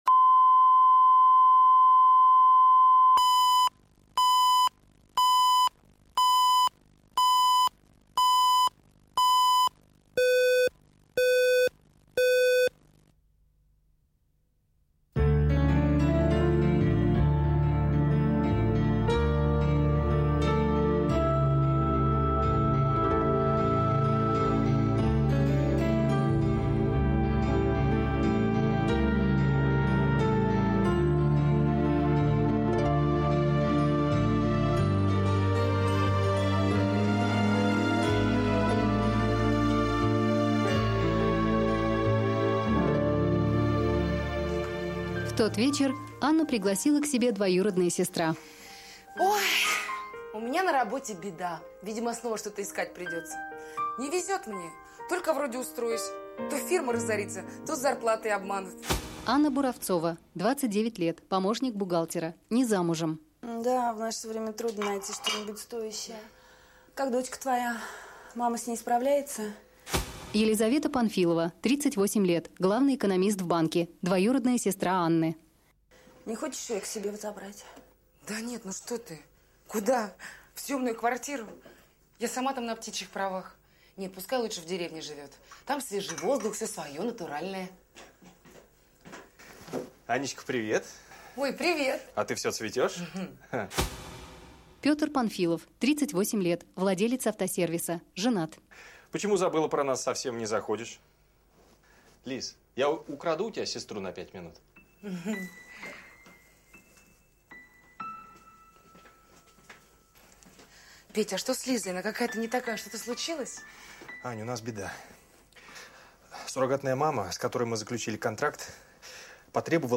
Аудиокнига Ребенок для сестры | Библиотека аудиокниг